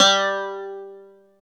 13 CLAV G3-L.wav